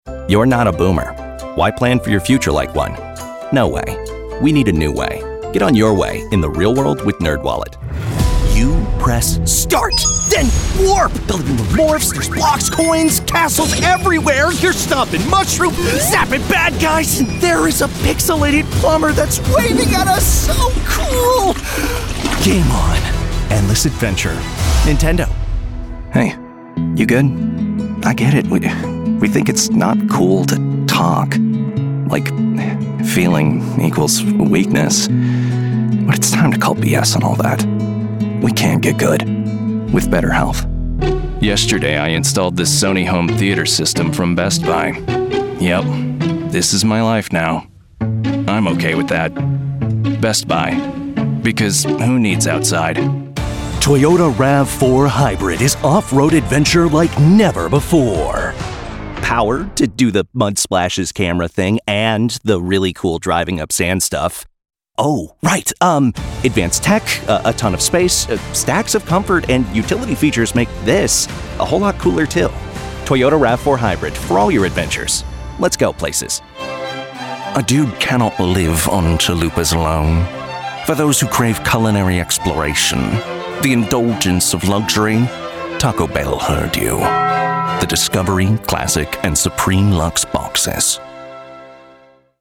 Equipment Neumann U87, Neumann TLM 103, Sennheiser MKH416, UA Apollo Twin X, Custom-built recording booth